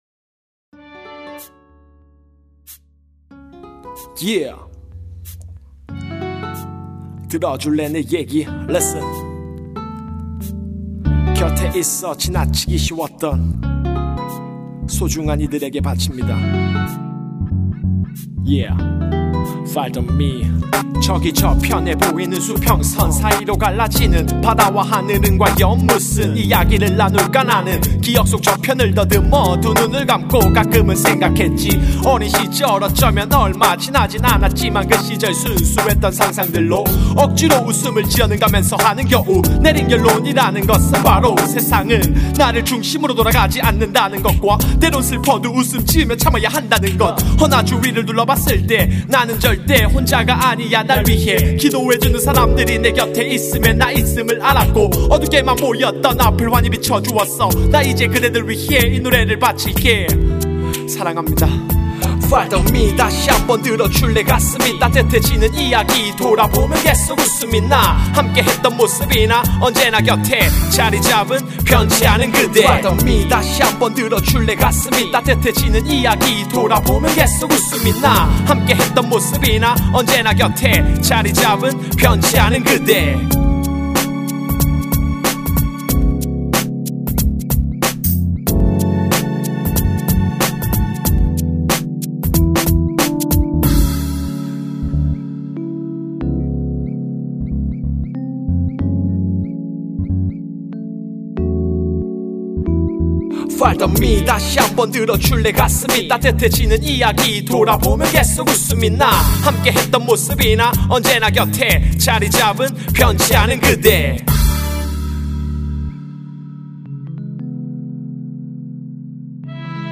목소리 참 좋으신데 전체적인 흐름이 약간 계속 반복 되는 느낌이 드네요..